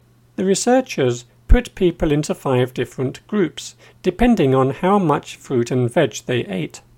DICTATION 6